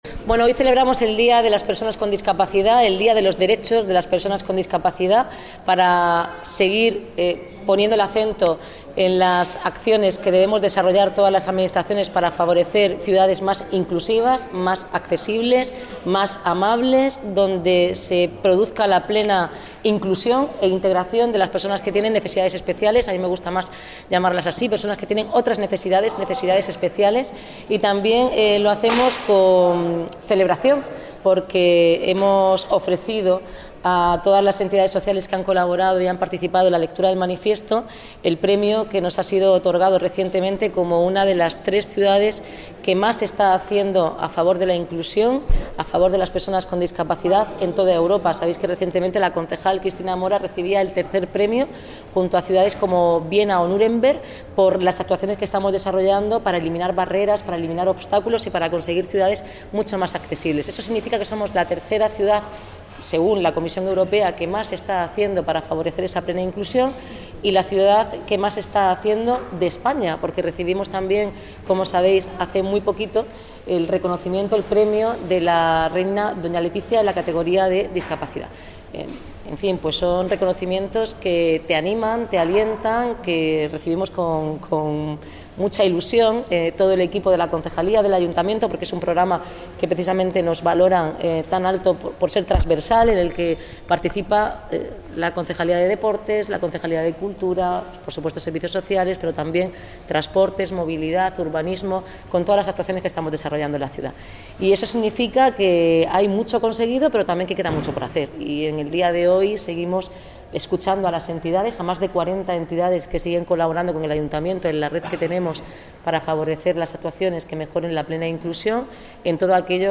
Audio: Declaraciones de la alcaldesa, Noelia Arroyo (MP3 - 7,54 MB)